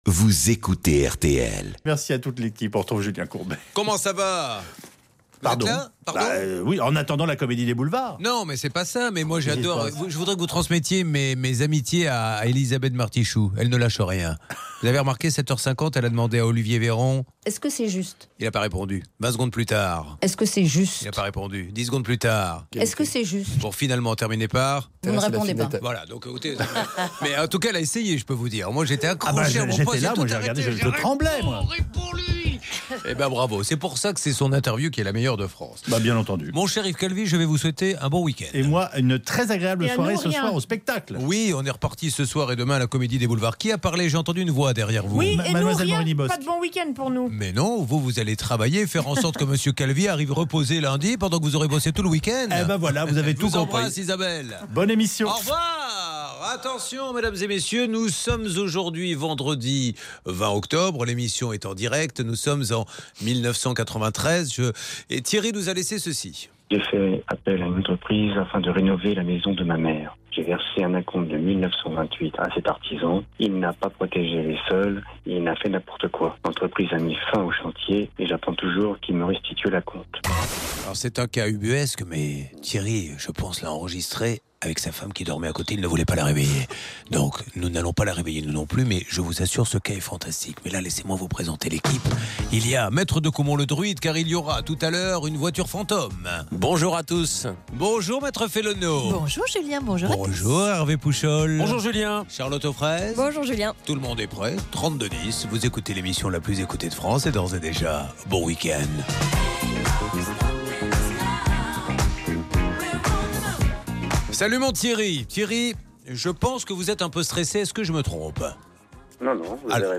Toute l’équipe règle vos problèmes d’auto et, notamment, celui d’un auditeur qui a loué un véhicule à l’occasion d’un déménagement. Or sa vitre droite, une fois ouverte, ne s’est jamais refermée.